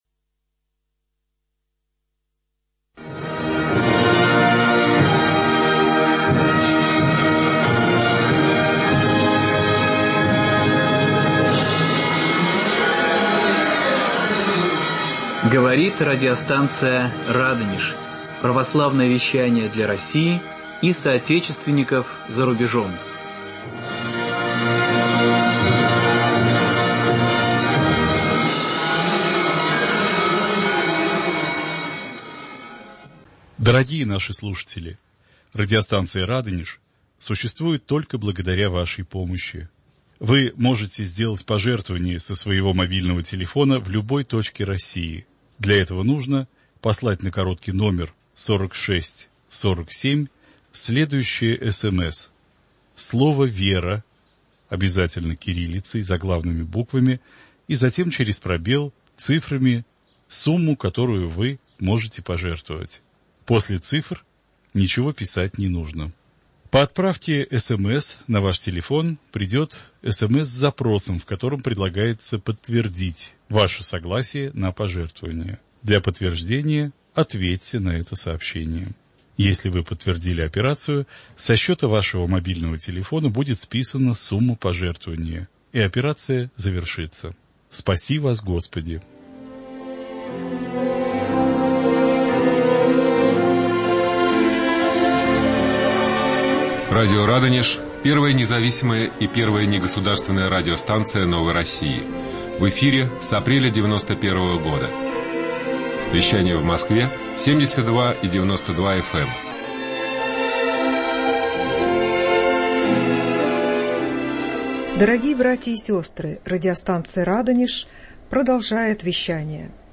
В беседе за круглым столом в студии